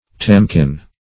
tamkin - definition of tamkin - synonyms, pronunciation, spelling from Free Dictionary Search Result for " tamkin" : The Collaborative International Dictionary of English v.0.48: Tamkin \Tam"kin\, n. A tampion.